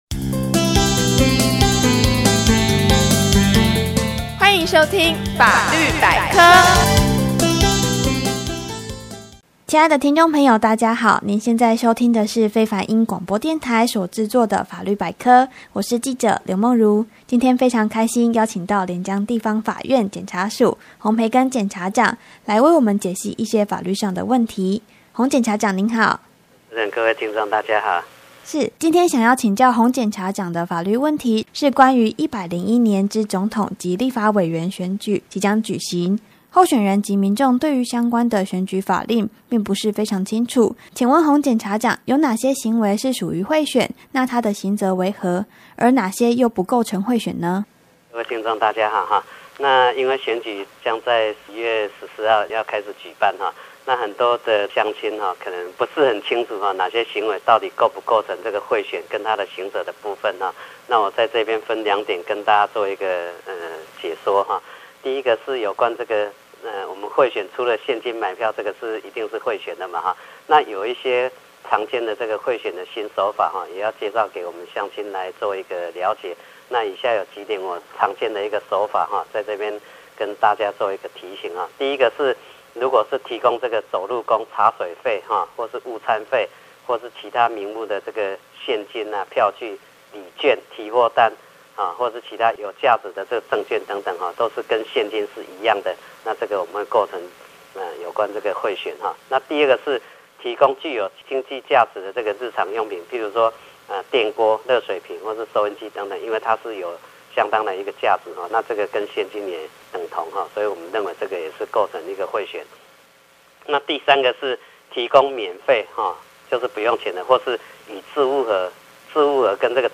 反賄選宣導專訪2.mp3 (另開新視窗)